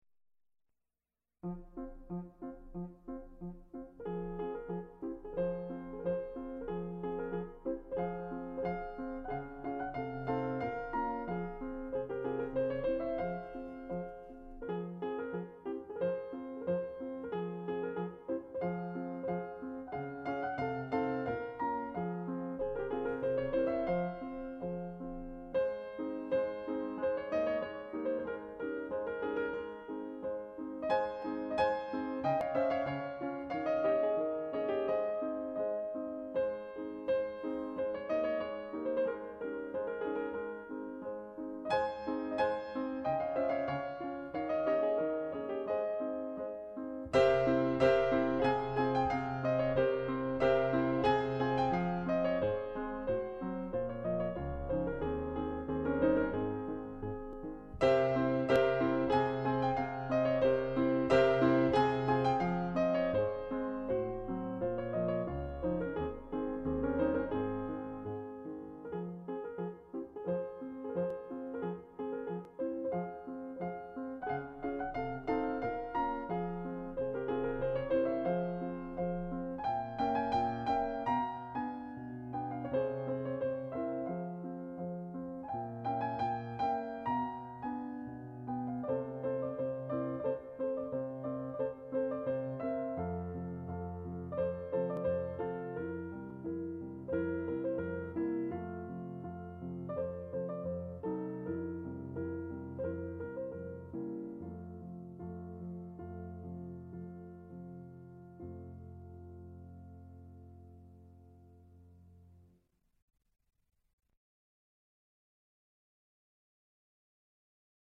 0293-琴名曲乐与之时.mp3